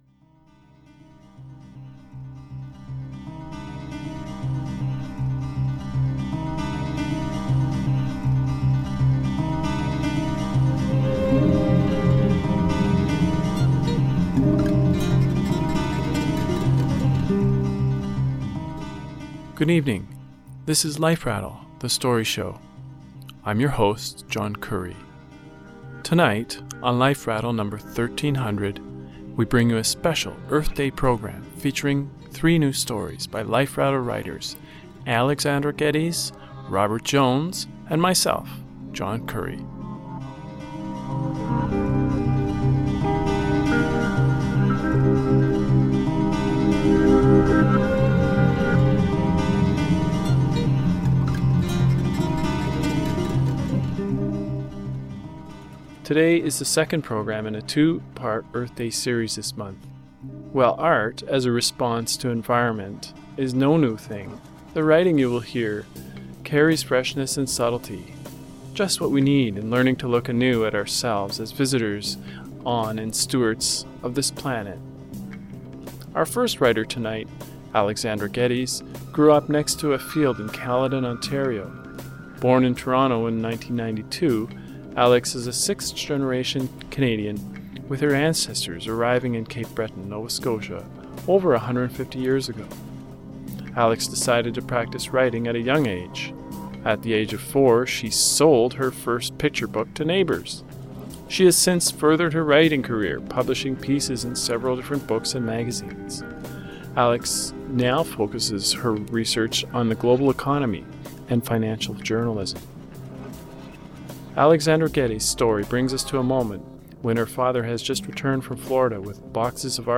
tonight's Show Life Rattle Program No. 1300 we bring you the second of our two part Earth Day series